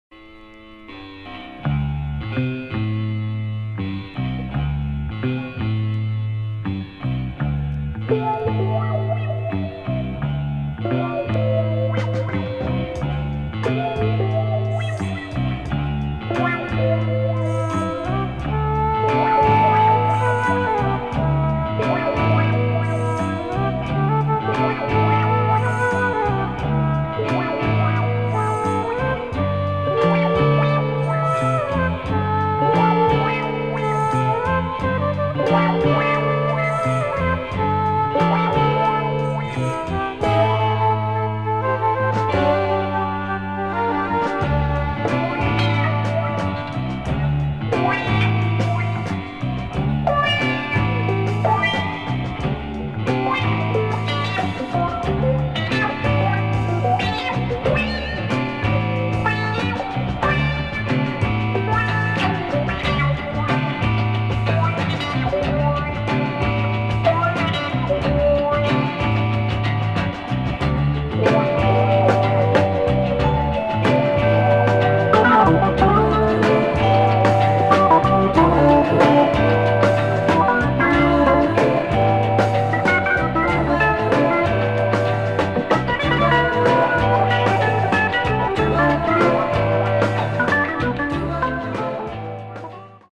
with a beautiful spacey intro, and splendid voices